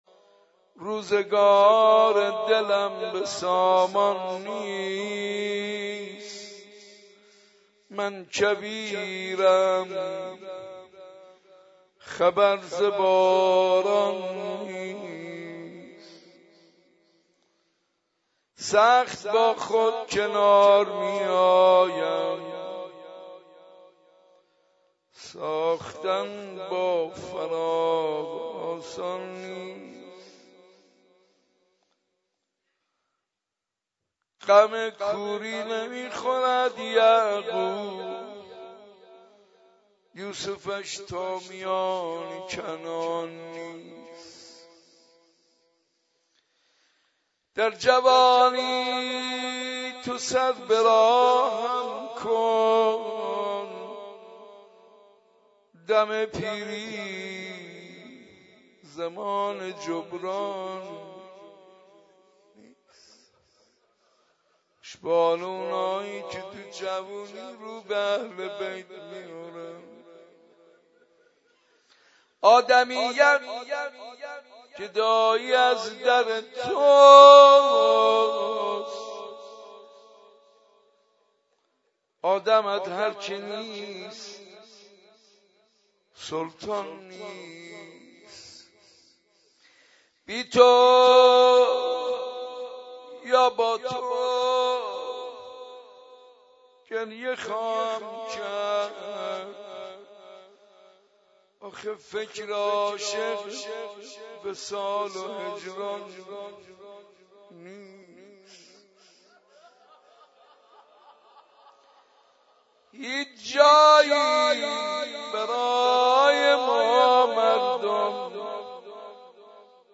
مراسم هفتگی حسینیه صنف لباس فروشان
روضه حضرت معصومه(س)